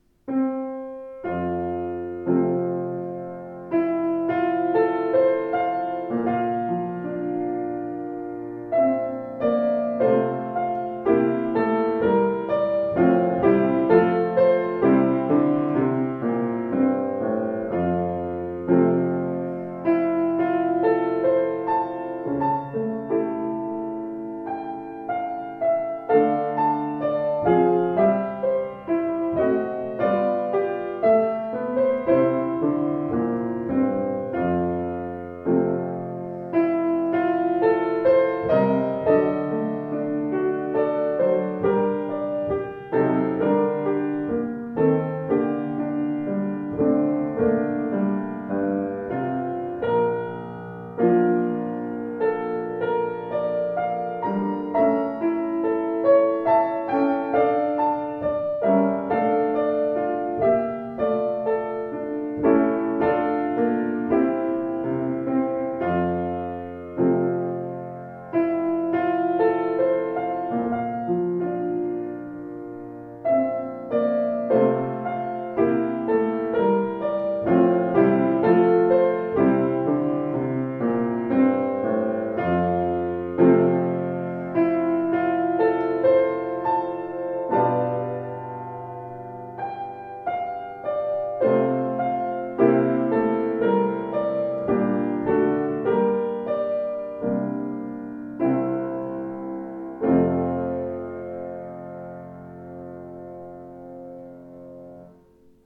Klangproben eines anderen, baugleichen Steinway Z: